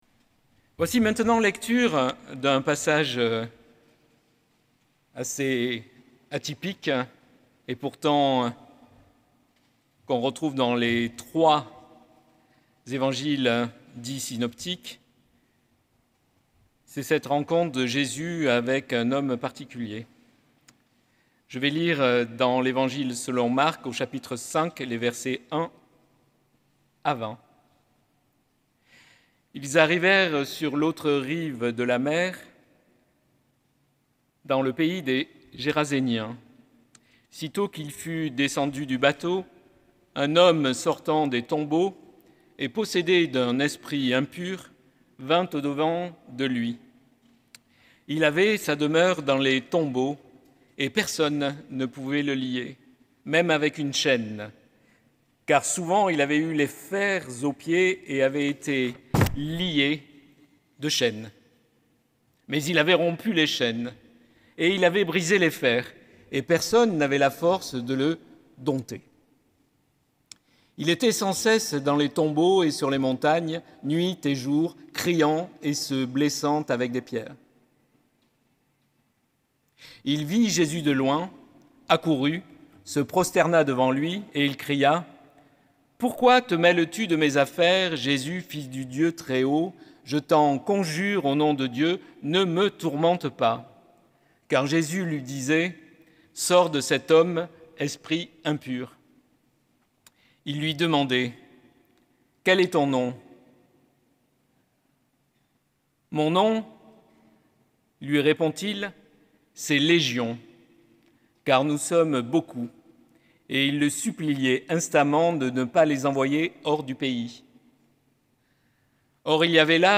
L'humain des tombeaux, prédication donnée à L'Oratoire du Louvre (Paris)
Prédication pour l’Oratoire du Louvre, le 1er juin 2025.